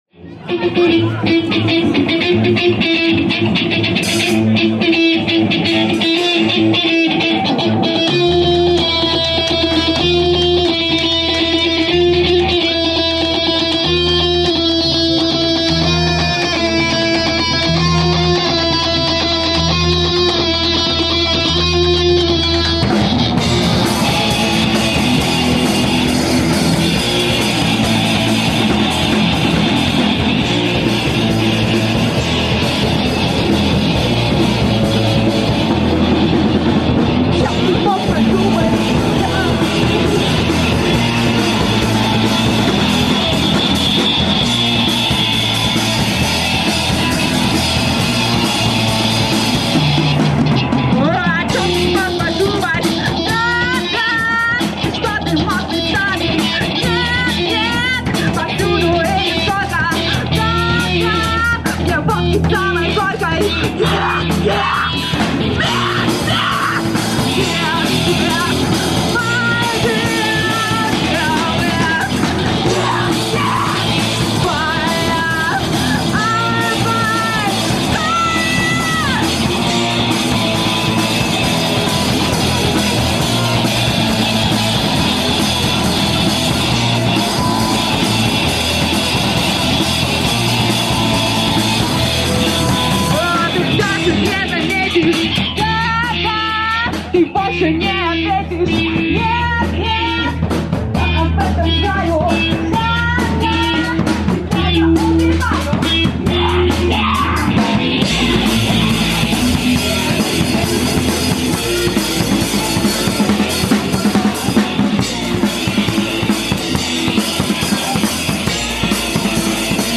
Live на крыльце (04.05.06)